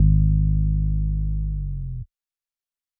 Metro 808s [Bomb].wav